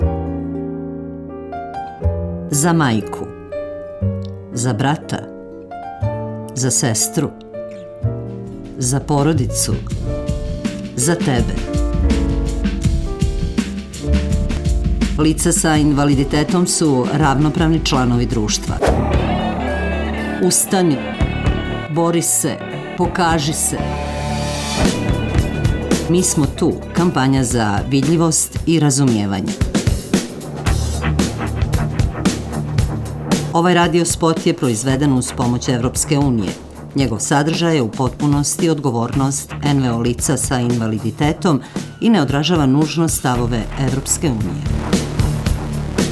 RADIO SPOT: